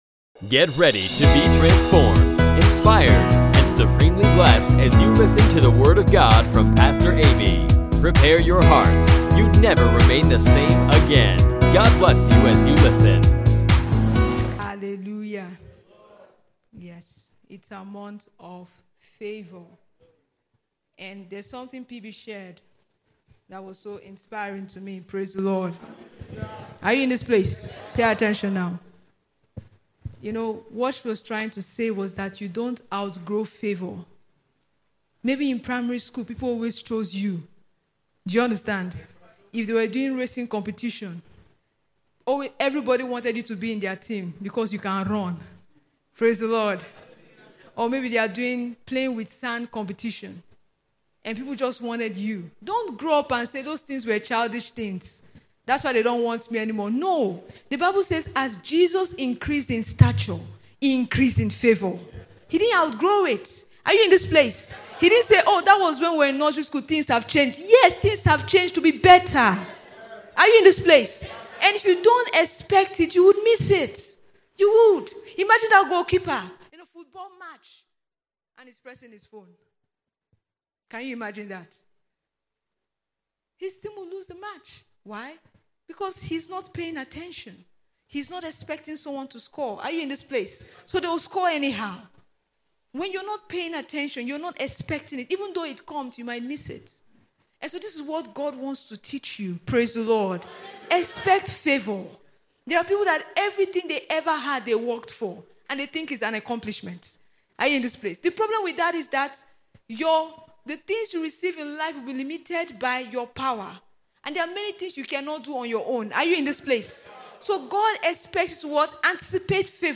Pastor teaches on the word is Active